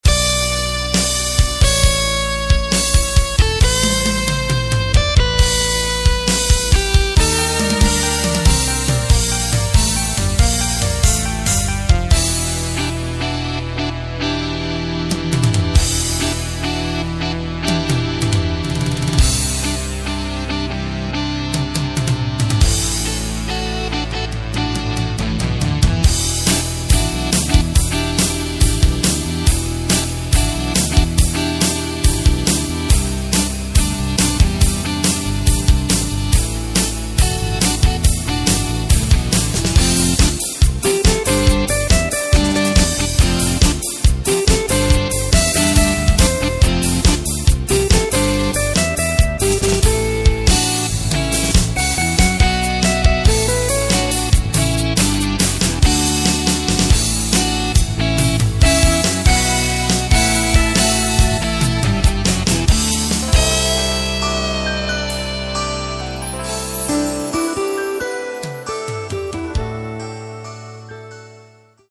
Takt:          4/4
Tempo:         135.00
Tonart:            C
Rock-Pop aus dem Jahr 1979!
Playback mp3 Demo